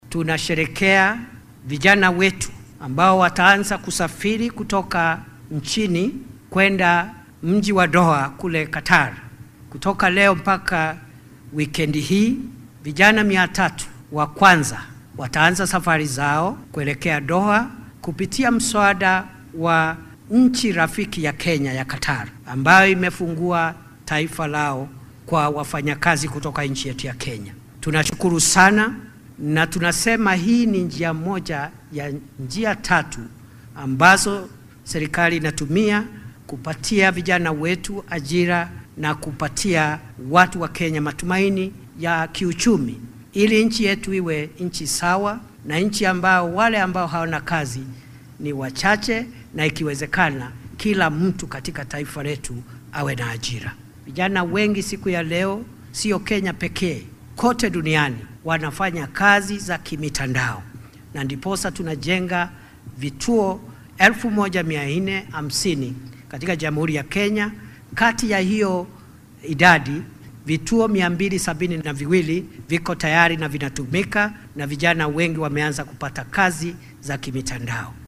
Arrintan ayuu ka sheegay munaasabad lagu qabtay xarunta shirarka caalamiga ee (KICC) ee ismaamulka Nairobi.